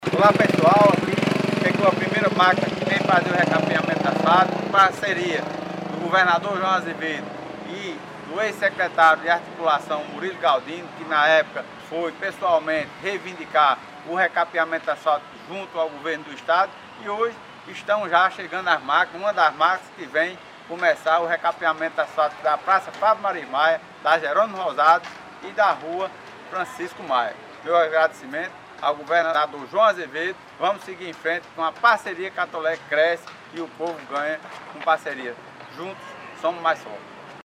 Ouça o que falou o Prefeito!
Prefeito-Laurinho-Maia.mp3